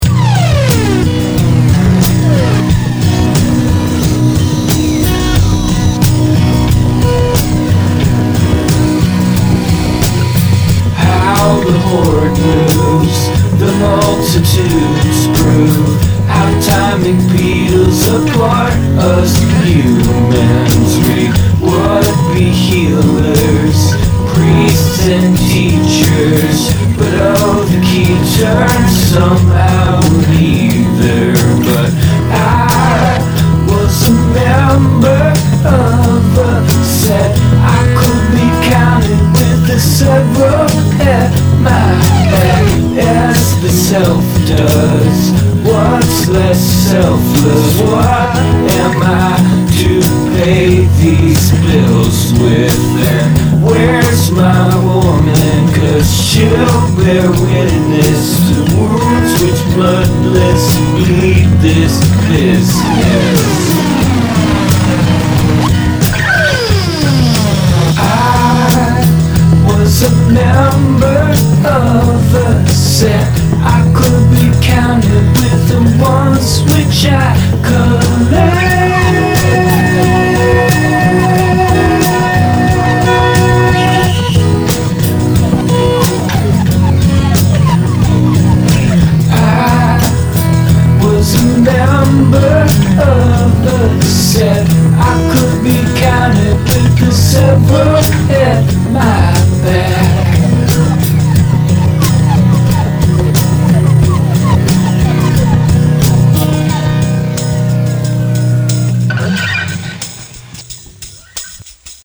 verse: F, C, F, C, F, C, G7, C
chorus: F, Am, G7, F, Am, G7
verse, chorus, verse, chorus, chorus
hey thanks for the comments man! yeah i'm digging on this too. it was very dashed off. i have a few things cooking, but haven't finished anything in a while so i decided to bang out a simple song. i wrote the chords in like two seconds. they lyrics came pretty quickly too once i got going. my approach to it was pretty lazy, which i think is appropriate for the feel of the song. i was mostly going for the sounds of the words and then was a little surprised to see something that seemed somewhat meaningful at the end. i picked out a drum loop, and laid down four tracks of acoustic guitar and put a bunch of pulsing modulating effects on them. then when i tried to do a synth lead i realized that i hadn't tuned the instrument, so i just did some noisy stuff, which actually sound kind of cool. vocals are triple tracked. i agree that this song could go on a bit more, and i think the ending could be stronger, it just sort of has an obligatory double chorus and then vamps through the end. sounds a bit thoughtless. probably because i didn't think much about it. i think i'll sit on this a while and then maybe come back to it.